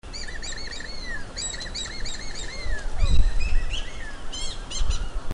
オガサワラヒメミズナギドリの鳴き声と動画
オガサワラヒメミズナギドリの鳴き声（約5秒）（MP3：130KB）
ミズナギドリ類は特徴的な鳴き声を持っています。